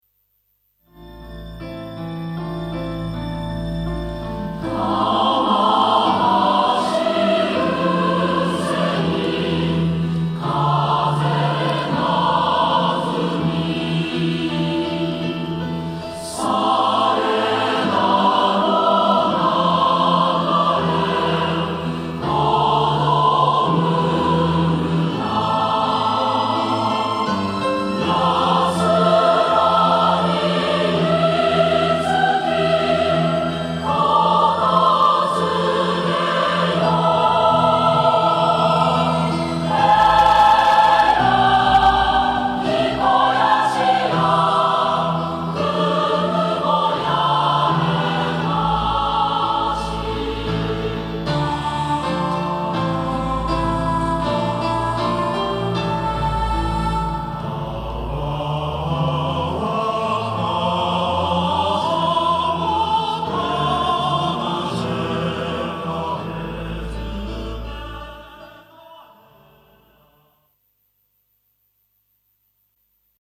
Chorus/Symphonic Works Reference CD